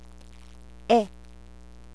segol "e" as in egg or met